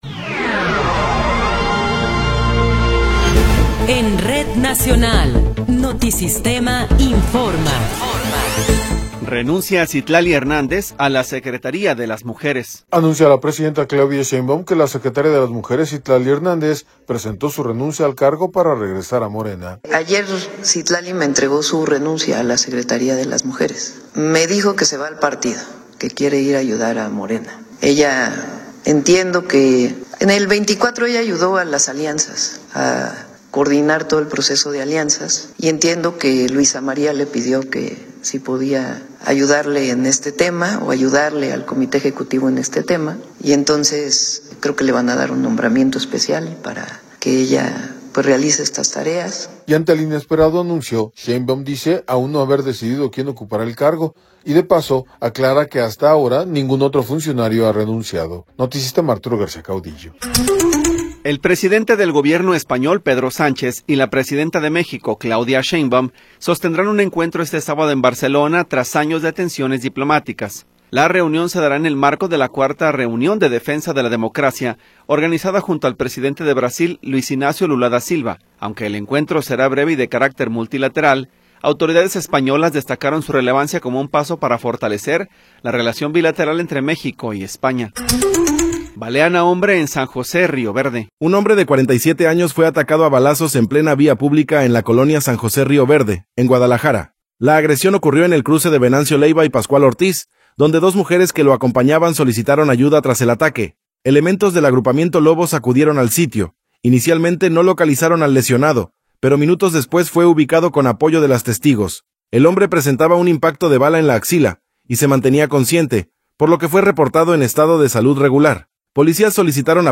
Noticiero 11 hrs. – 16 de Abril de 2026